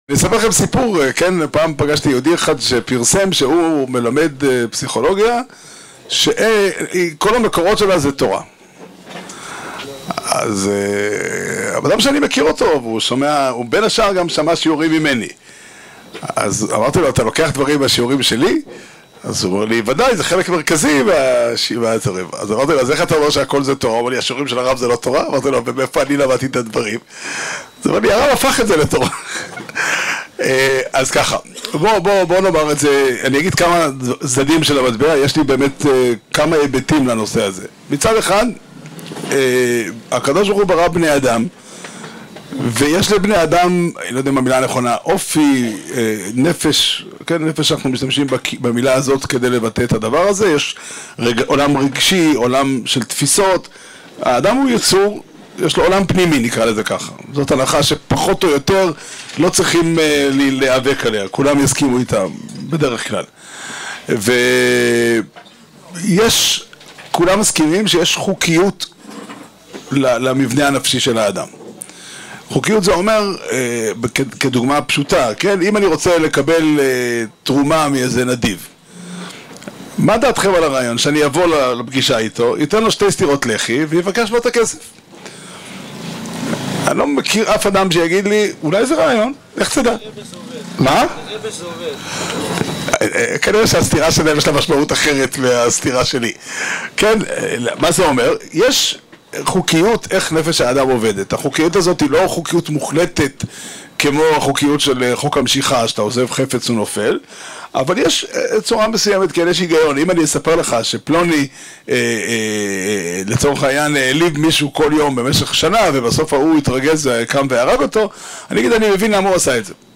השיעור נמסר במסגרת הלימוד השנתי בליל הו"ר תשפ"ה בסוכה בהר נוף